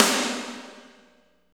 48.04 SNR.wav